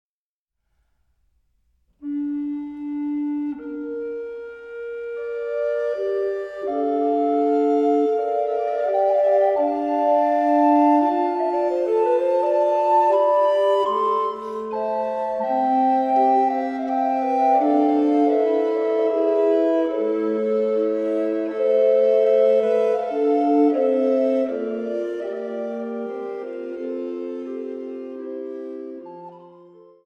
Fünfstimmiges Blockflötenconsort